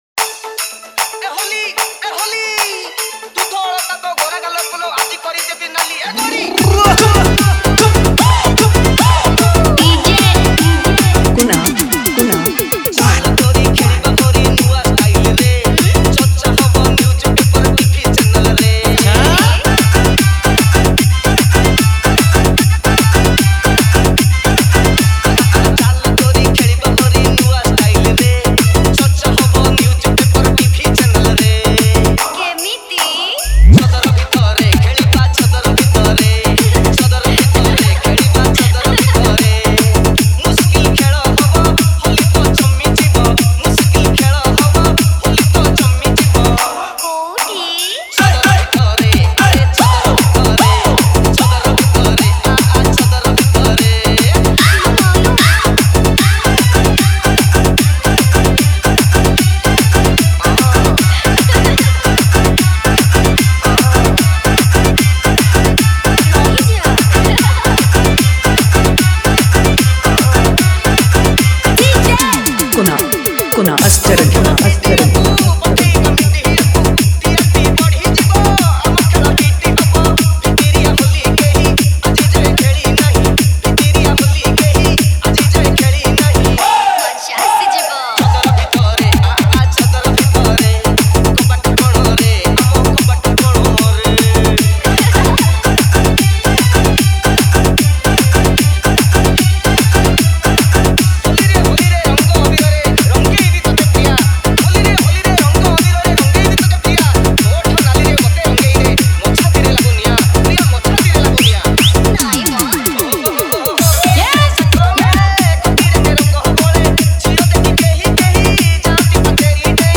HOLI SPECIAL DJ SONG